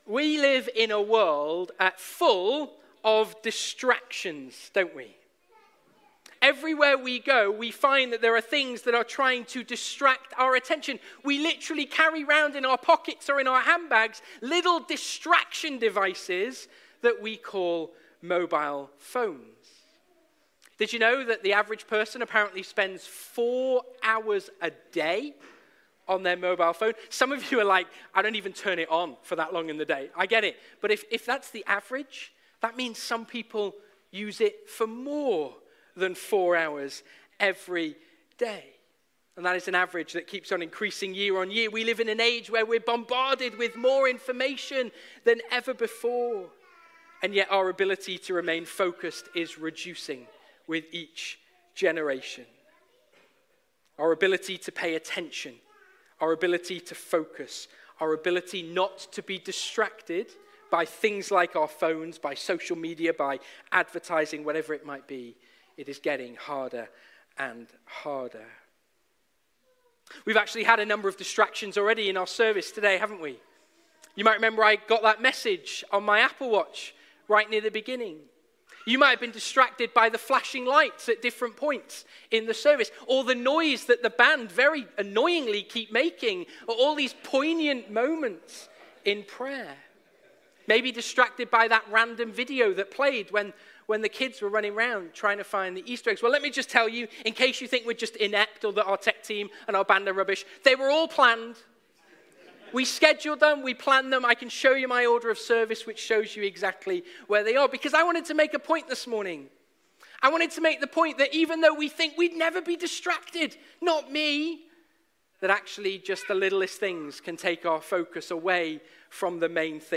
Live stream Passage: Matthew 28:1-15 Service Type: Sunday Morning « Living Faith